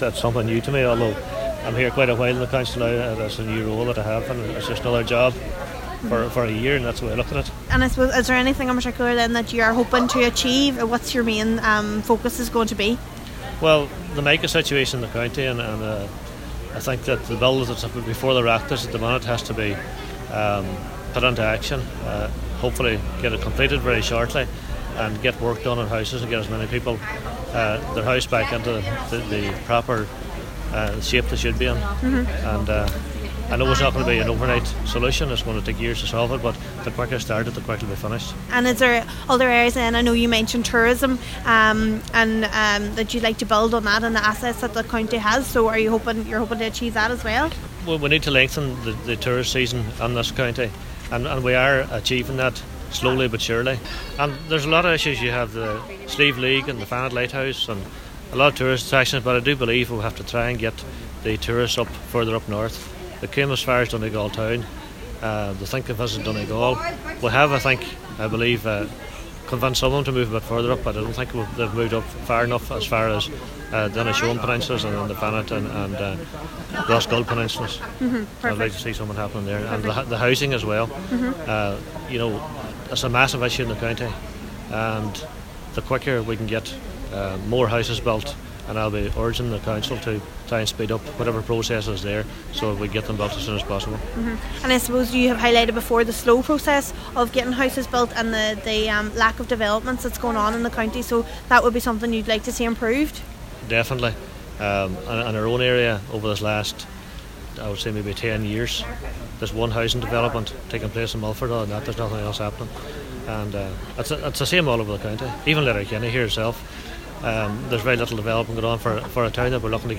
Cllr Liam Blaney says that mica and housing will be two of his main focuses….